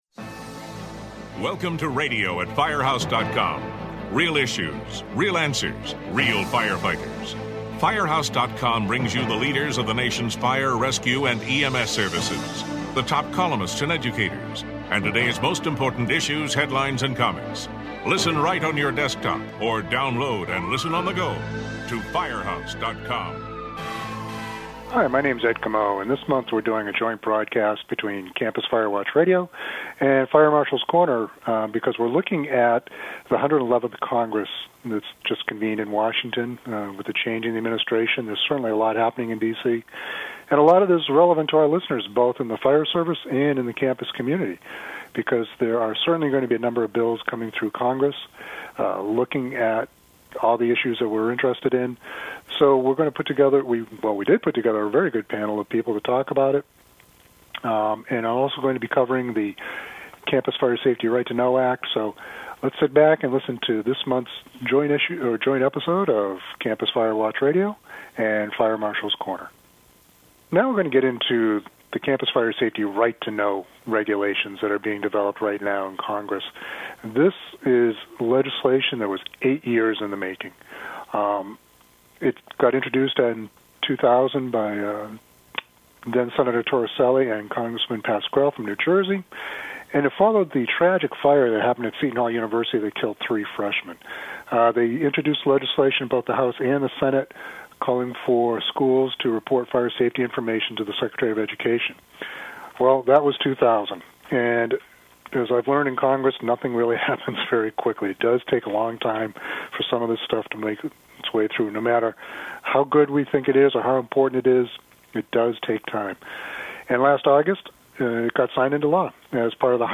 A panel of guests provides their perspective on the 111th Congress, including the legislative priorities and tips on how to work with Congress in getting legislation passed.